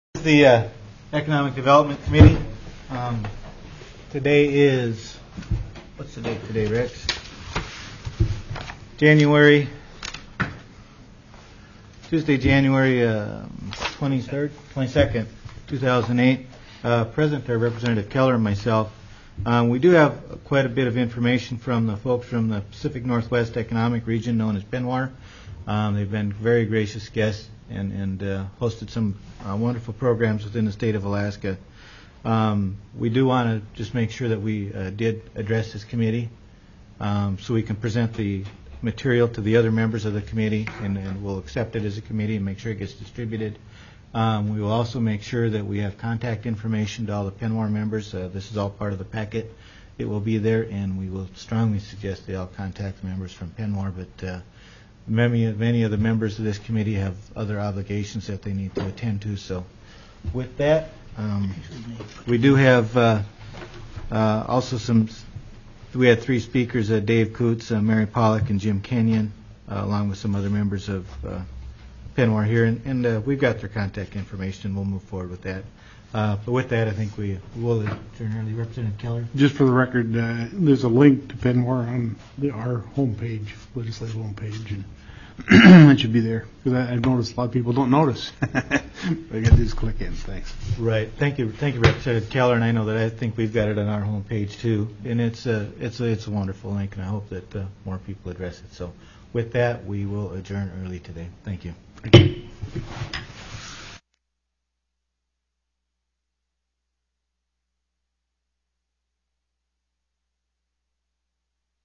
+ Overview by Pacific NorthWest Economic TELECONFERENCED